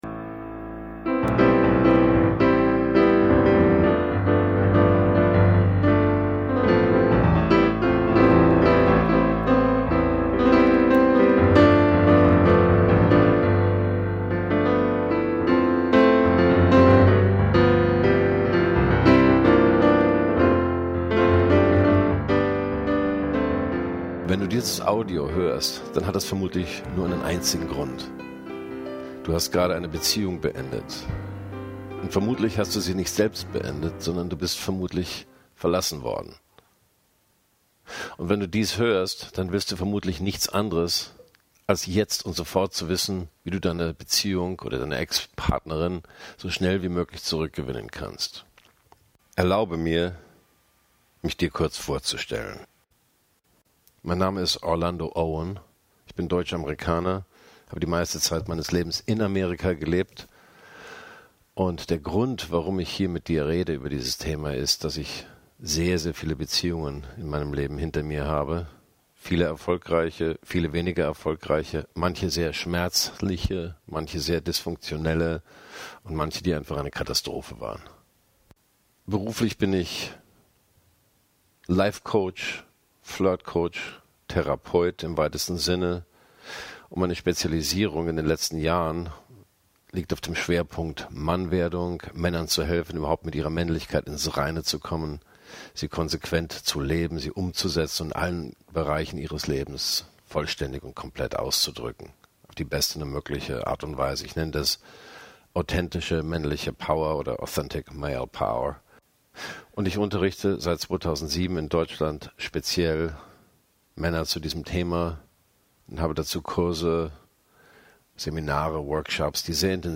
einen Vortrag zu halten zum Thema "Ex zurück gewinnen".